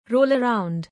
roll-around.mp3